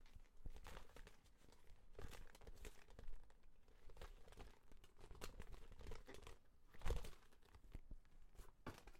Shaking_rubber_mask_gently_1
OWI rubber shake shaking sound effect free sound royalty free Memes